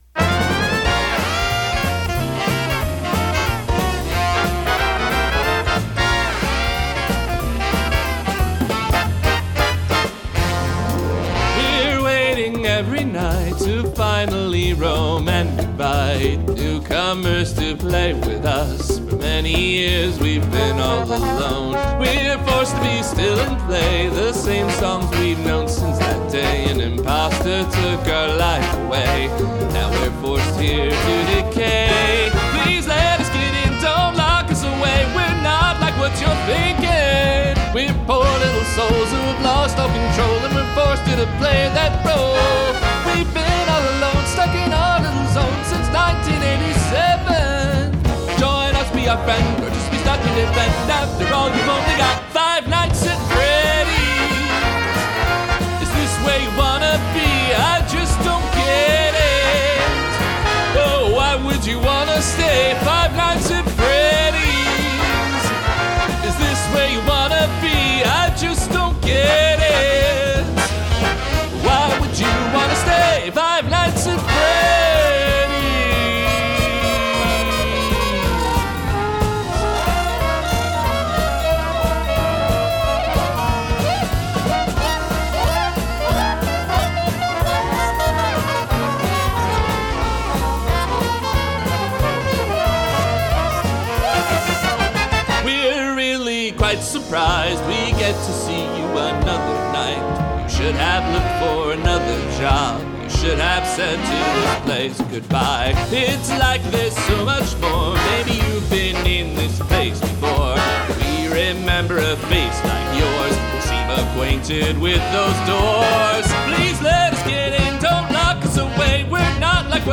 Me singing along to the instrumental of the big band version